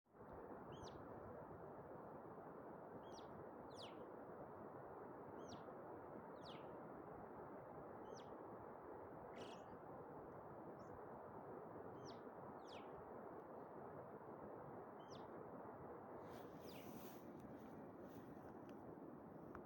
Alpendohle
Besonders charakteristisch sind ihre pfeifenden Rufe.
Alpendohlen-am-Grossglockner-3.mp3